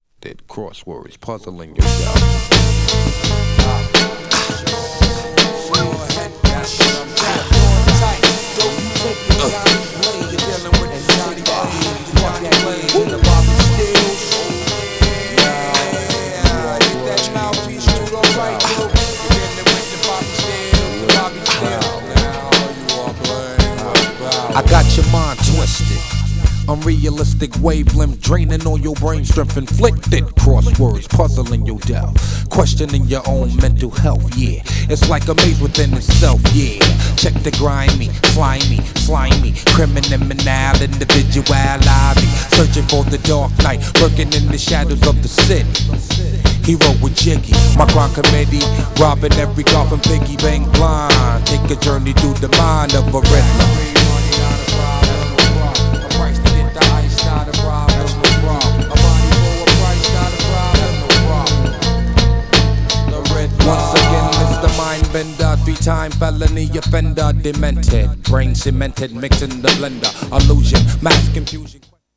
ヘビーなサウンド!!
GENRE Hip Hop
BPM 86〜90BPM